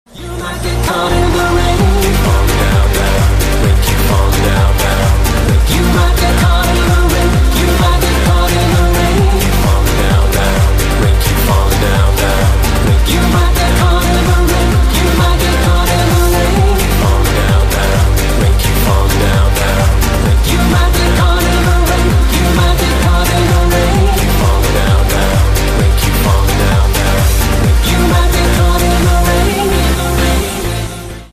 • Качество: 128, Stereo
ритмичные
громкие
dance
электронная музыка
club